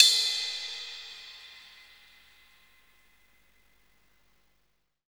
CYM LTN C0KL.wav